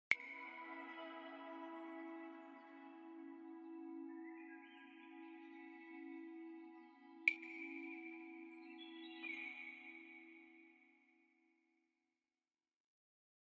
motion animation of growing flowers sound effects free download
Mp3 Sound Effect motion animation of growing flowers in blender